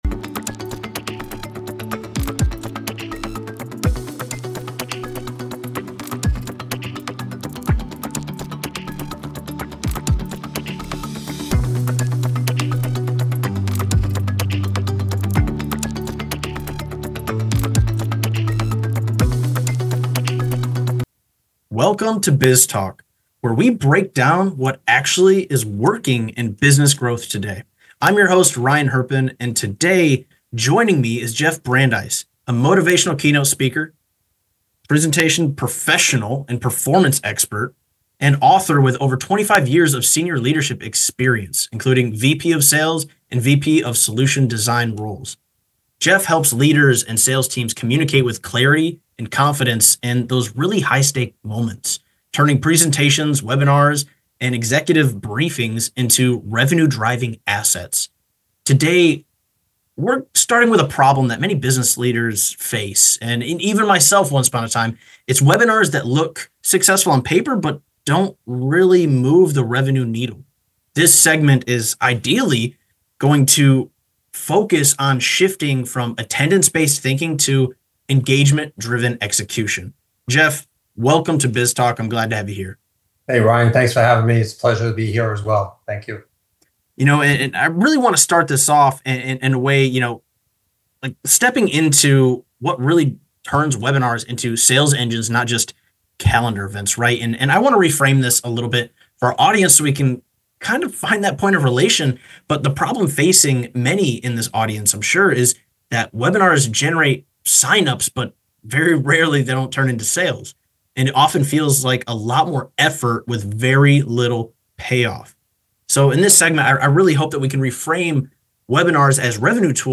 This conversation shifts the focus from attendance-based metrics to engagement-driven strategy, revealing how trust, structure, and storytelling impact conversions.